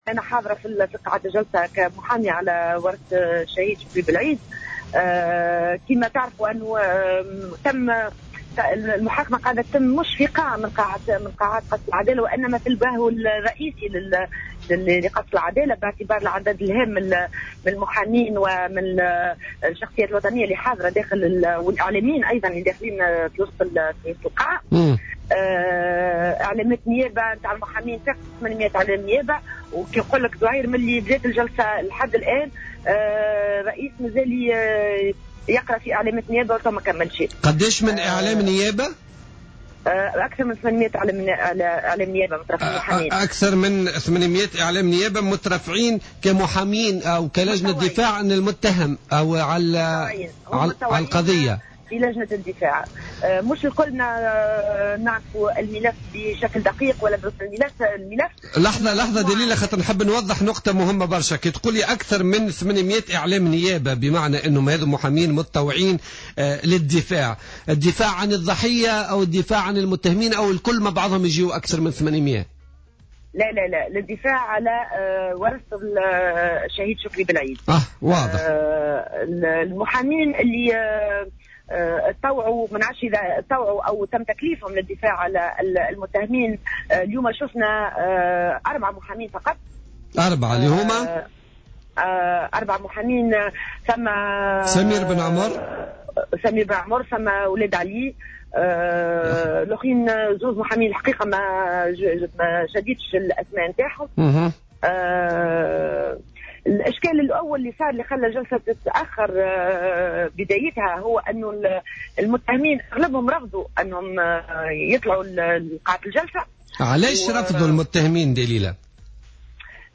مداخلة لها في برنامج "بوليتيكا"